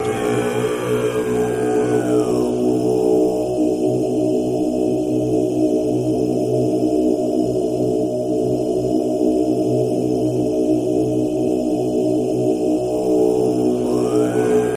Monk Voice Low Chanting